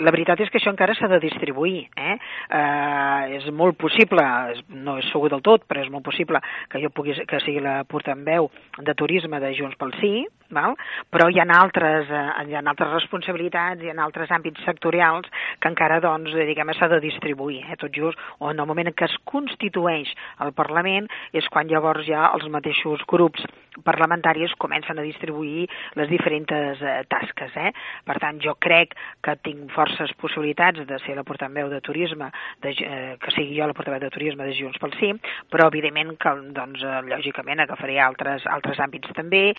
En una entrevista que podreu escoltar el proper dilluns al Bon Dia de Ràdio Calella, Montserrat Candini ha avançat que amb tota probabilitat serà la portaveu de Turisme del grup parlamentari de Junts pel Sí: “La veritat és que això encara s’ha de distribuir.